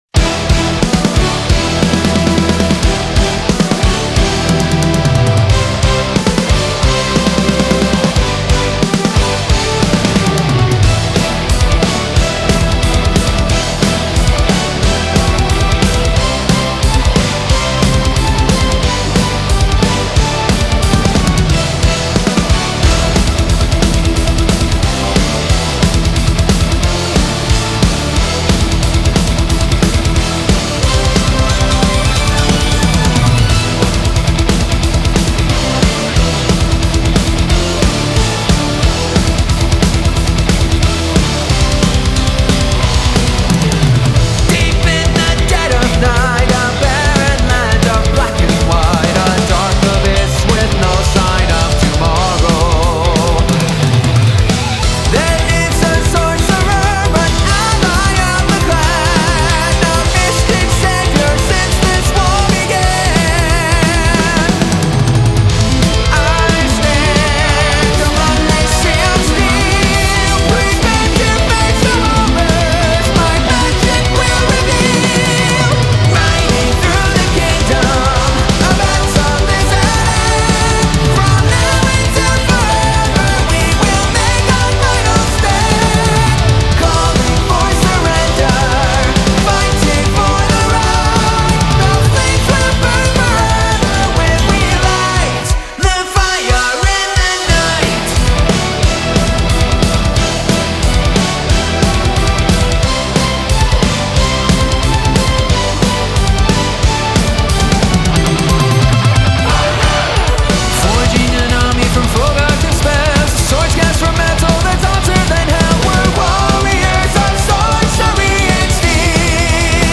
Epic Symphonic Power Metal